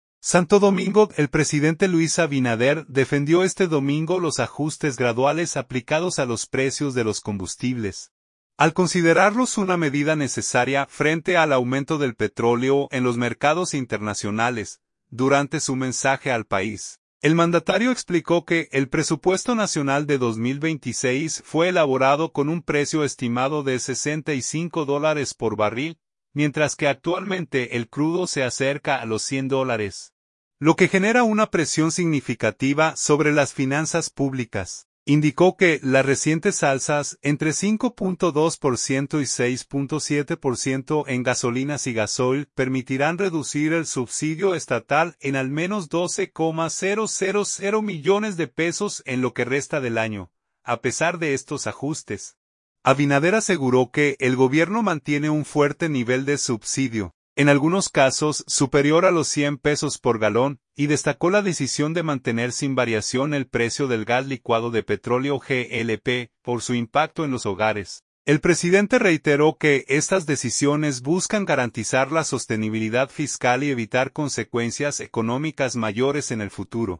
Durante su mensaje al país, el mandatario explicó que el presupuesto nacional de 2026 fue elaborado con un precio estimado de 65 dólares por barril, mientras que actualmente el crudo se acerca a los 100 dólares, lo que genera una presión significativa sobre las finanzas públicas.